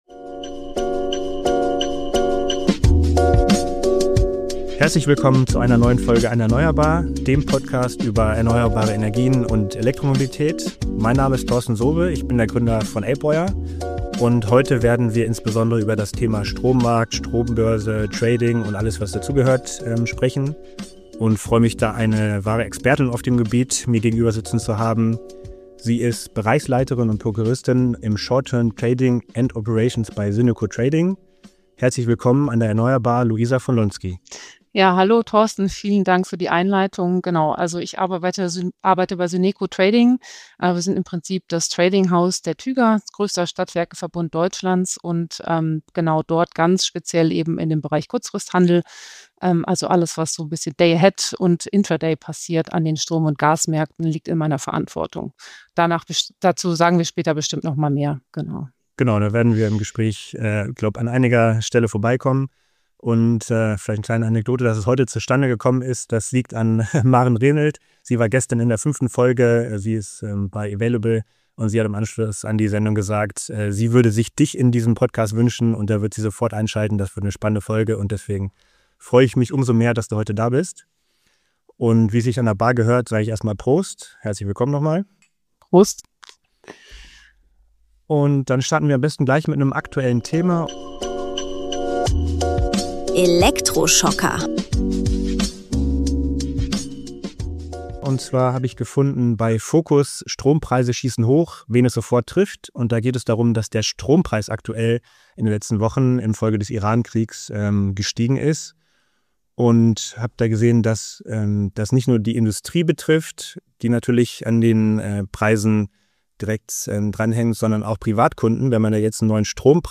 Zukunftstalk